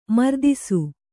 ♪ mardisu